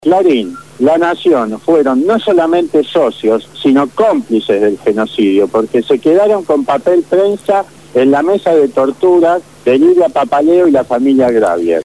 COLUMNA